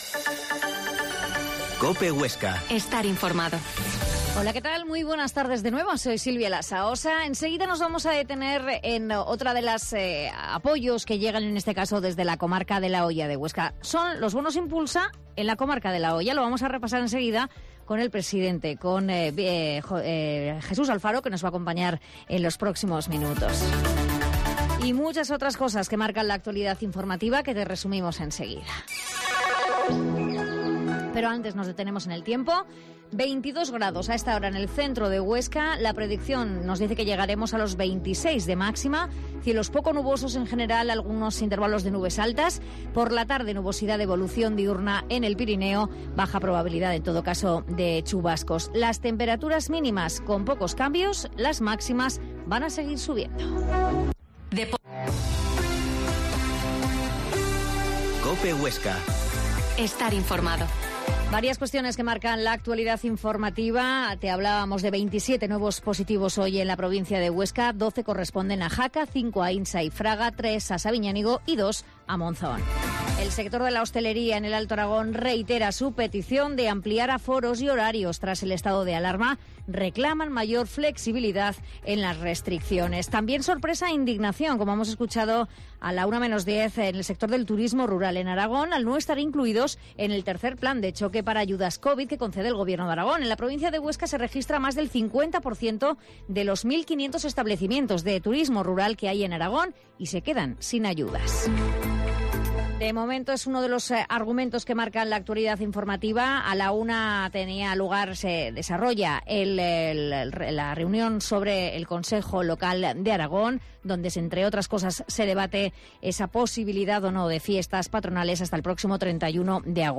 La Mañana en COPE Huesca - Informativo local Mediodía en Cope Huesca 13,20h.